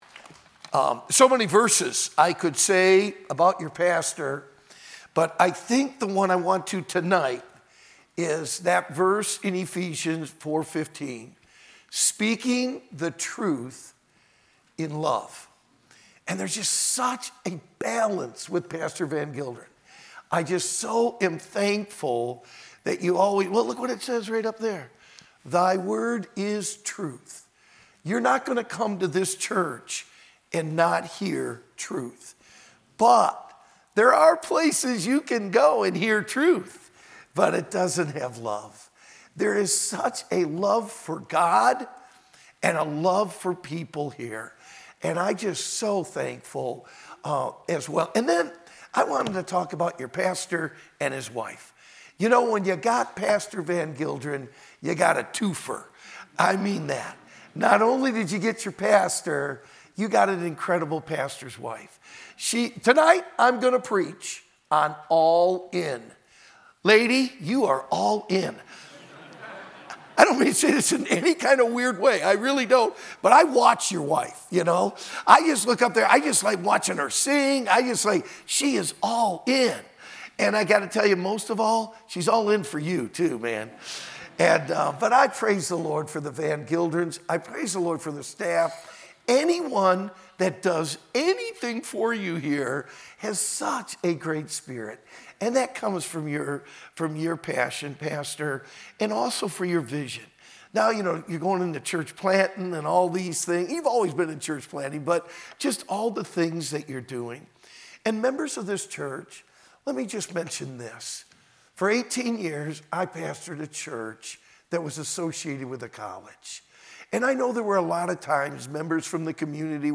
No Reserves Victory Conference 2026 Archives - Falls Baptist Church
Preached on Thursday, March 5, 2026